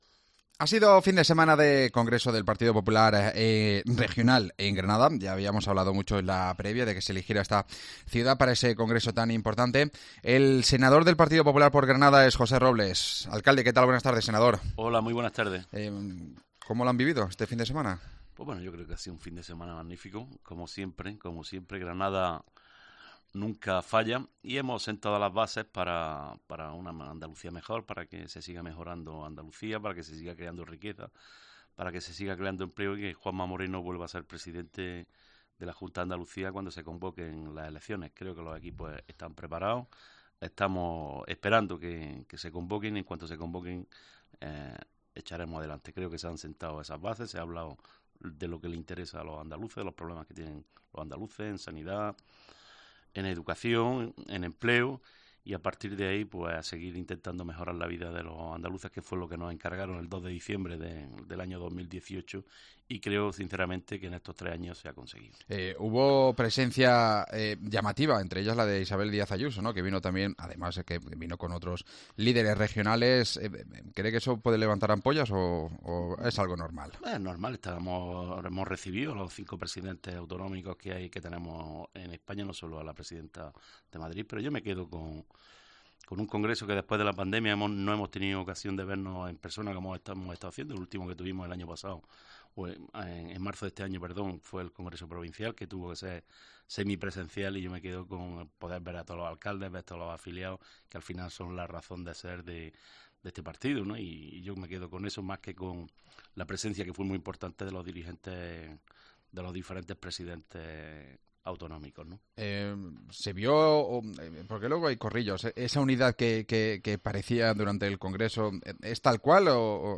AUDIO: Entrevista al Senador del PP granadino, José Robles, sobre la actualidad política